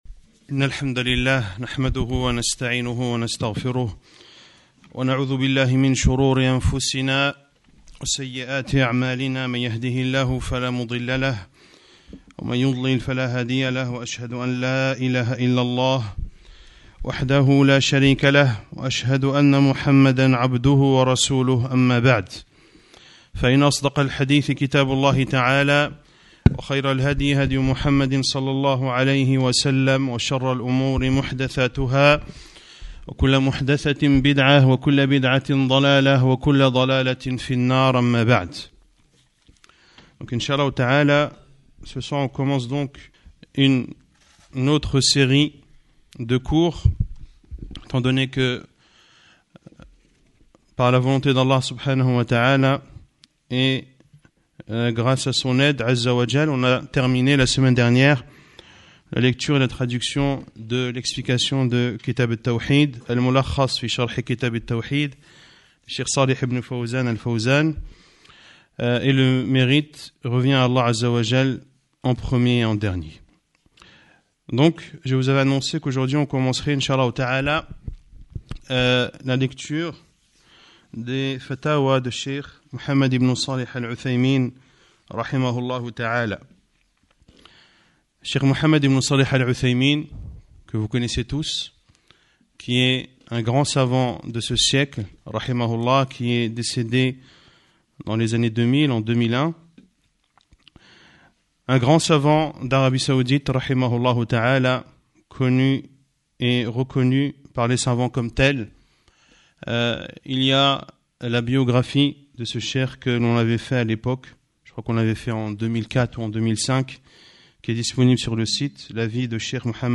tst.mp3 1 Cours 01 : - Le jugement des tableaux sur lesquels sont inscris le nom d'Allah ou des versets du Coran.